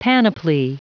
Prononciation du mot panoply en anglais (fichier audio)
Prononciation du mot : panoply